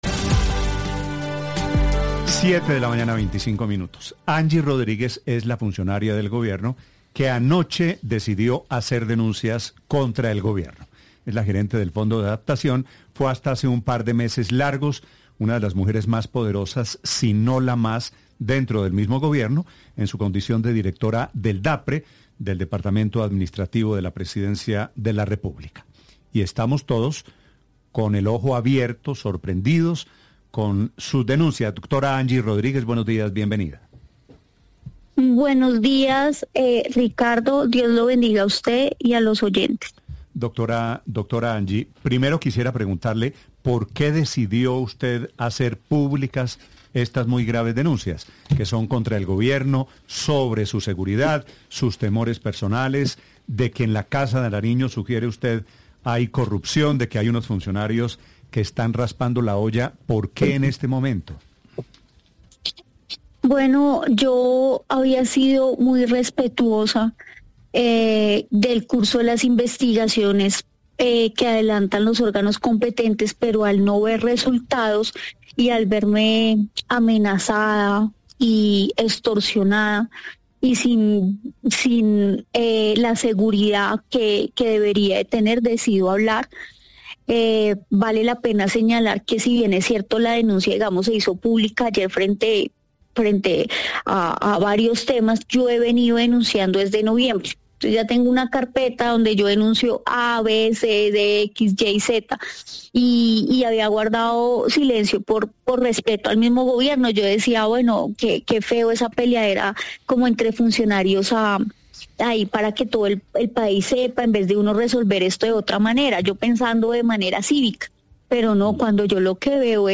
Entrevista-BluRadio.mp3